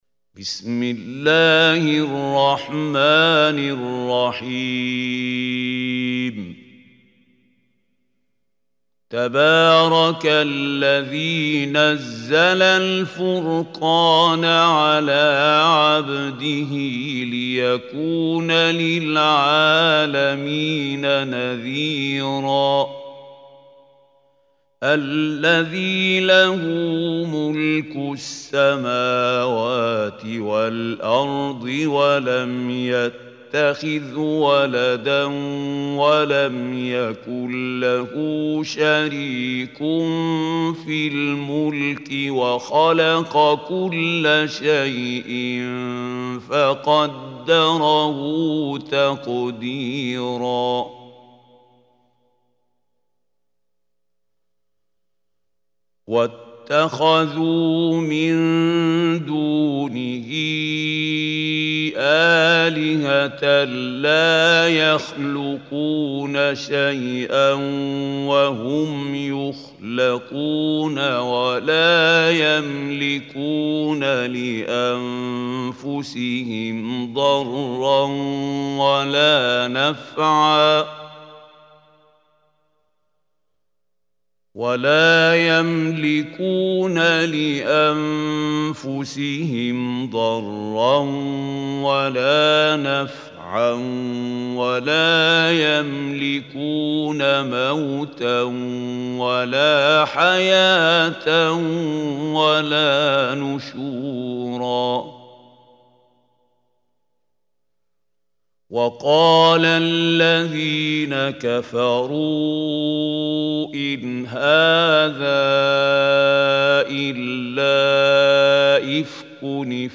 Listen beautiful recitation of Surah al Furqan in the voice of Mahmoud Khalil al Hussary.